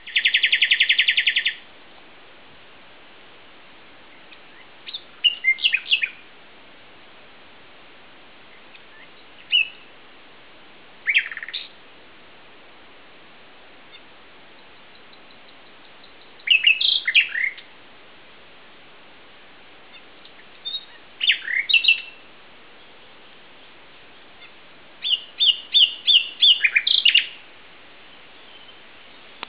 il 6 maggio scorso ho registrato questo canto.
E' il bellissimo canto dell'Usignolo (Luscinia megarhynchos)
Ottima registrazione.
E' proprio un bellissimo canto.